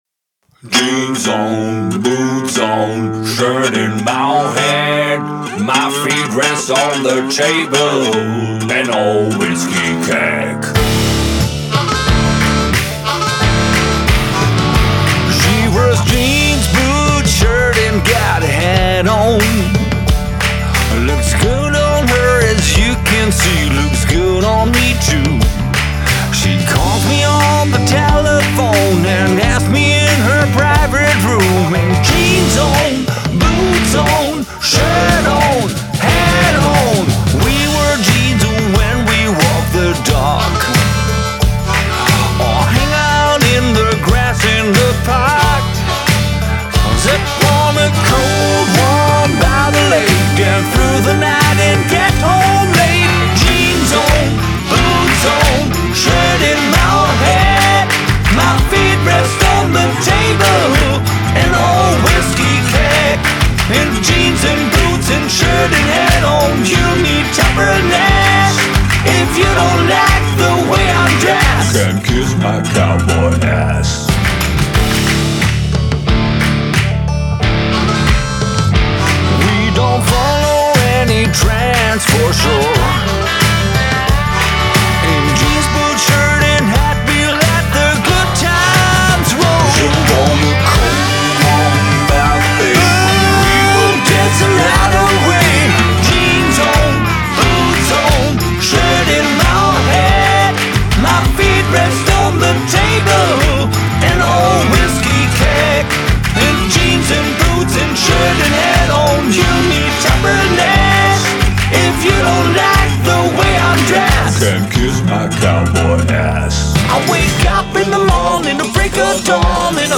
GENRE: NEW COUNTRY/SOUTHERN ROCK**